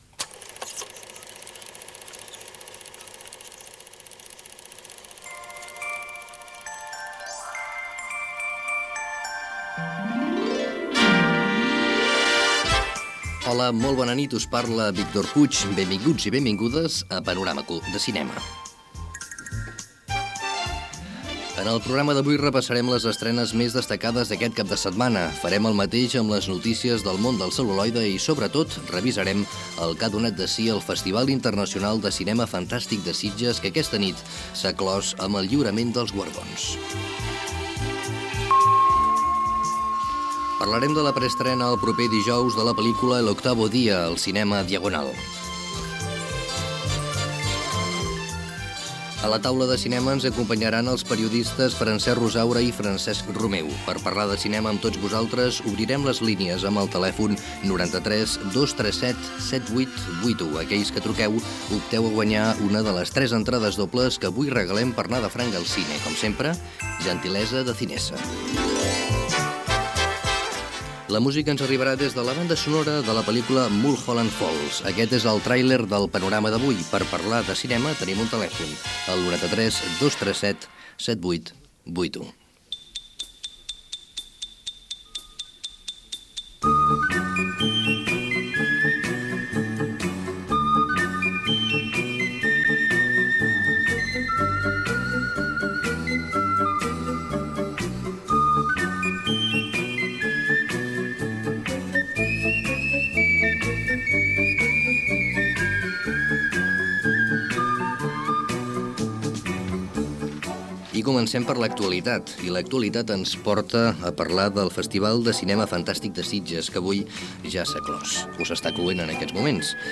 Sintonia, benvinguda, sumari de continguts, telèfon del programa, el Festival de Cinema Fantàstic de Sitges